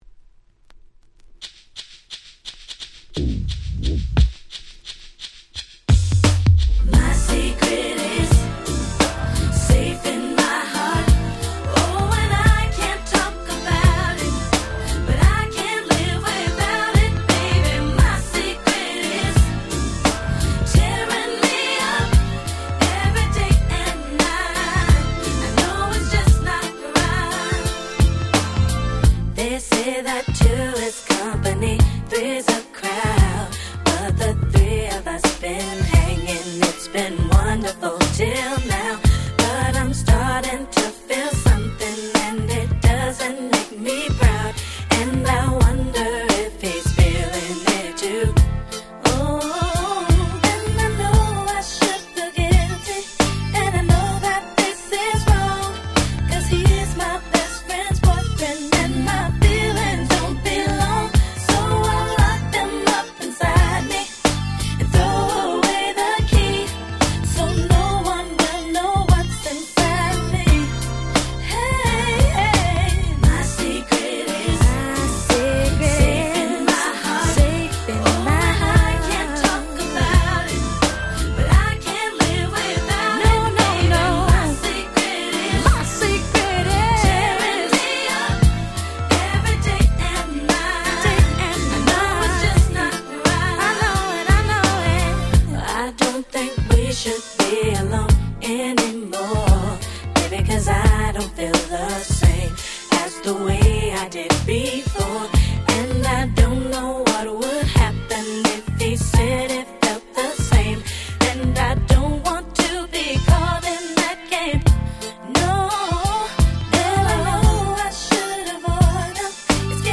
96' Very Nice R&B / Hip Hop Soul !!
96年に1枚のアルバムのみで消息を絶ってしまった女の子3人組。
ピーヒャラシンセが西の香りを漂わせる最高のHip Hop Soul !!